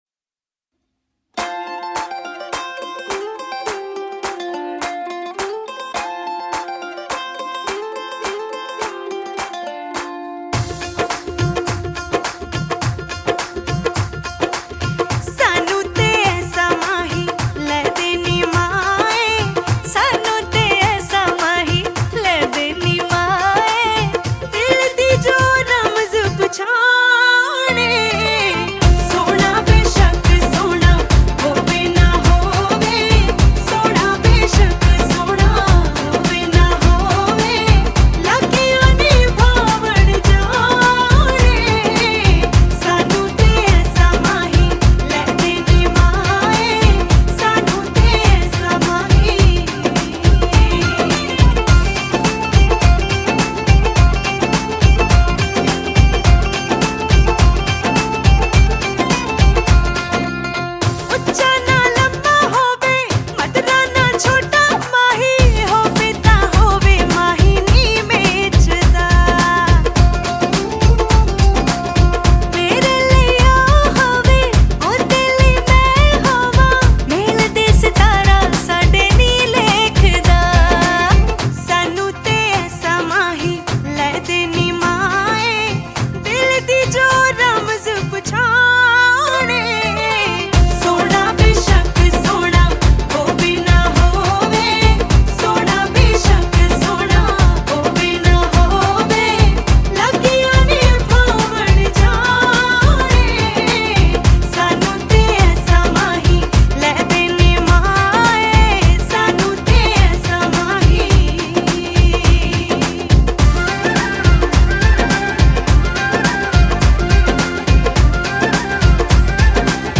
Song Genre : Punjabi Old Song 2